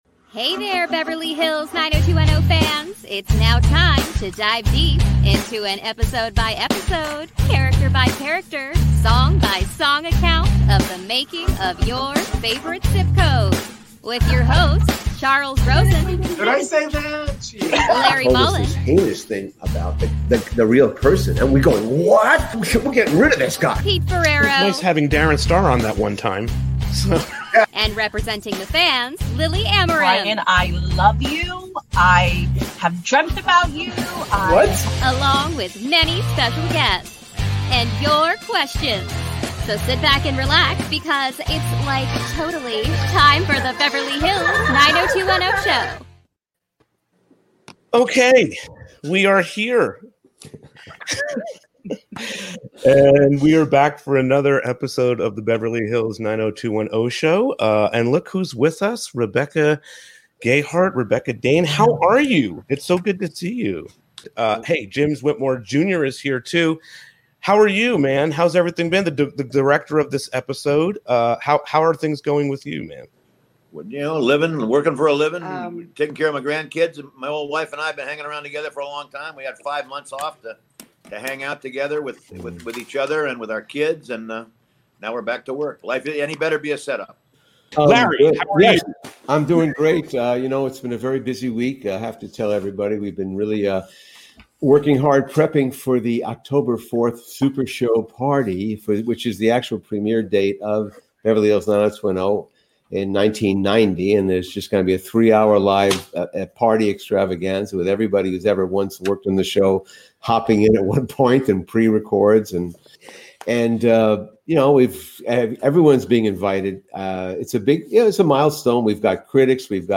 We are still managing to get ideal WiFi/audio levels.* Follow Us: Instagram Facebook YouTube Shop Our Merch!